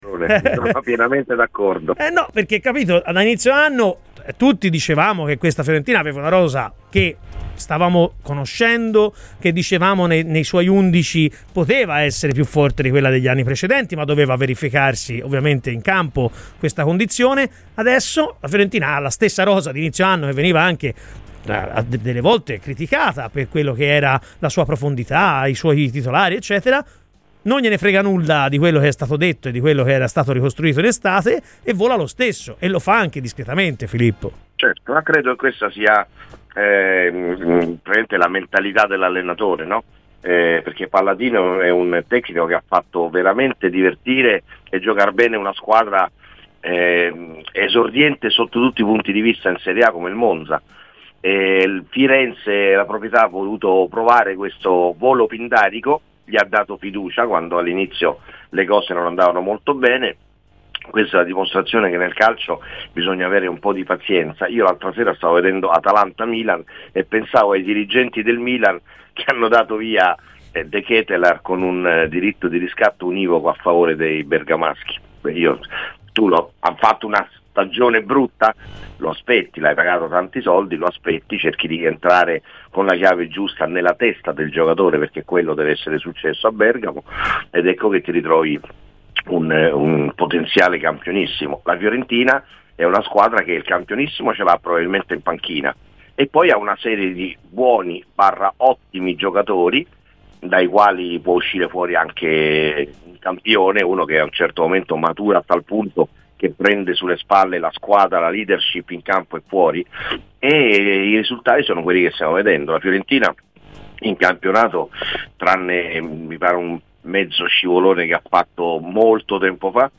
PER L’INTERVISTA COMPLETA ASCOLTA IL PODCAST